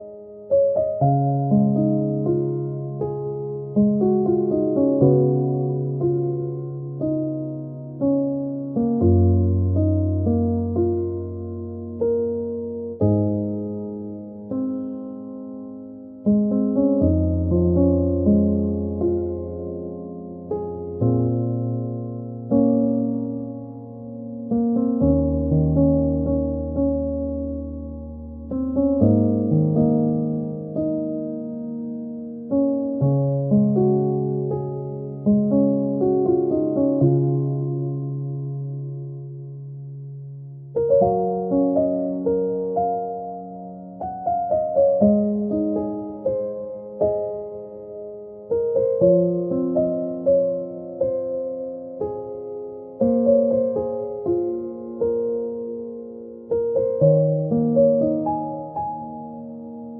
Listening to this album for relaxing and healing